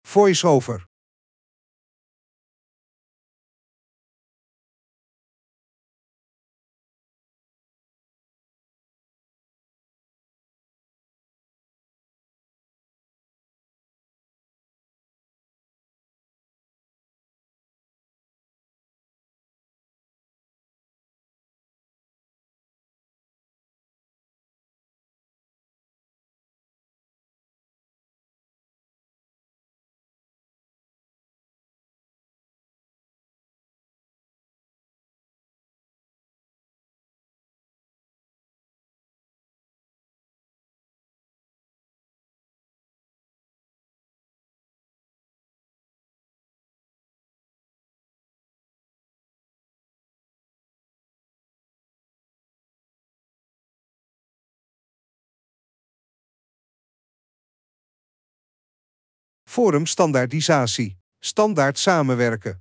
Voice-over